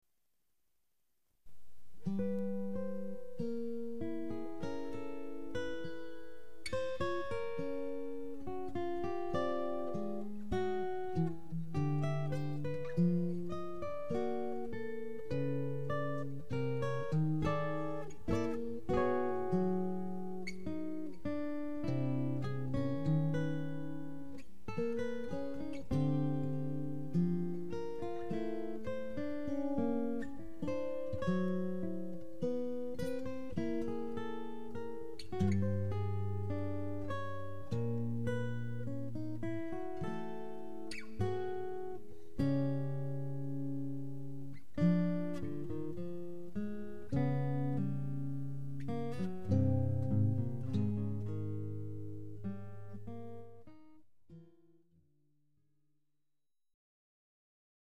Duet for 6- & 12-string Guitars